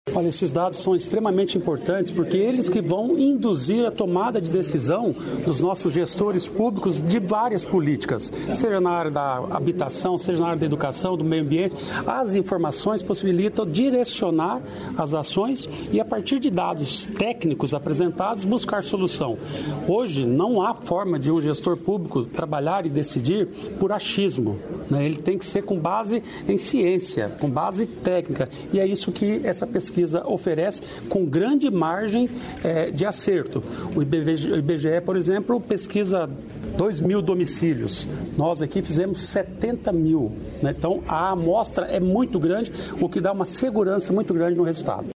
Sonora do secretário do Planejamento, Ulisses Maia, sobre pesquisa inédita